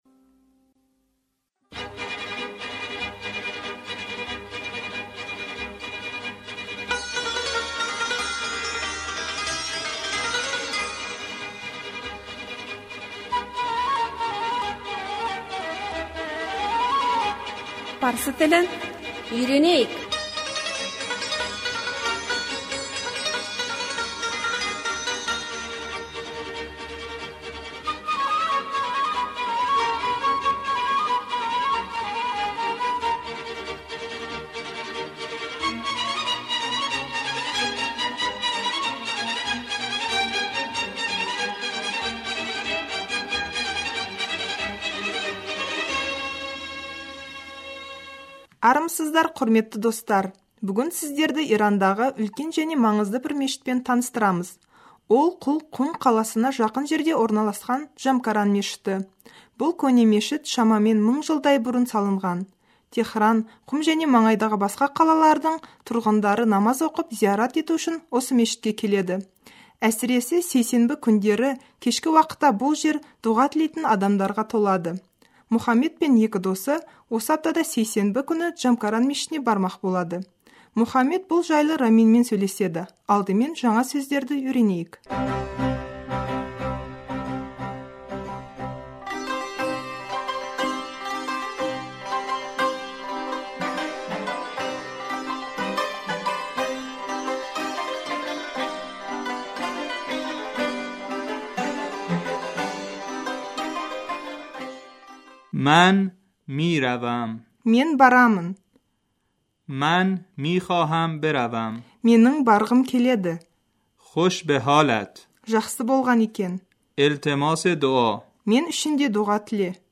Шайханада дәстүрлі музыка әуені естіліп тұр.